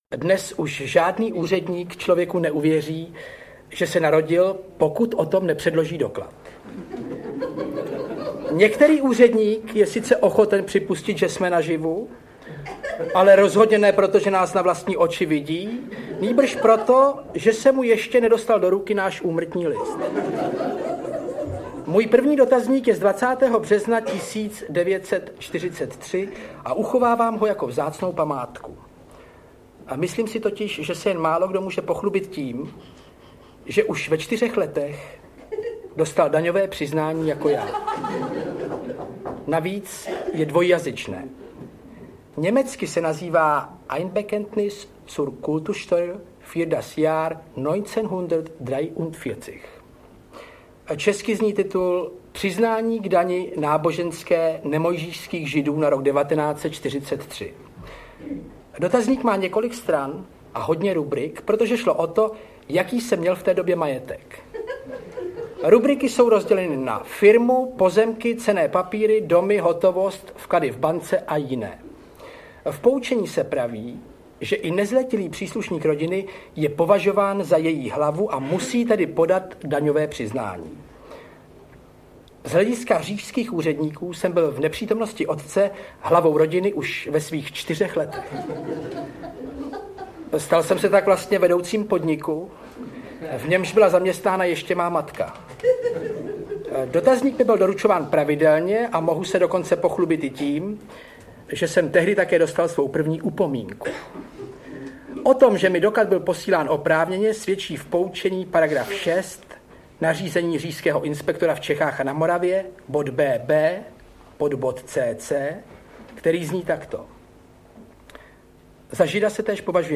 Audiokniha Má rodina a jiná zemětřesení - záznam šesti představení z pražské Violy, kde známý herec a televizní moderátor Jan Kraus vystupuje se svým bratrem Ivanem Krausem, autorem úspěšných humoristických knih
Ukázka z knihy
Při jejich interpretaci oběma bratry pak vzniká neopakovatelná atmosféra.
• InterpretIvan Kraus, Jan Kraus